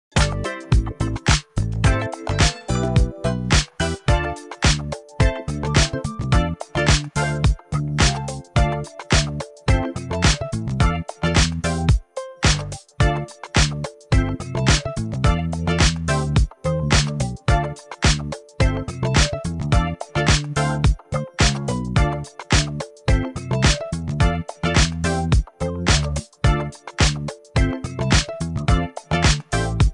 Music Ringtones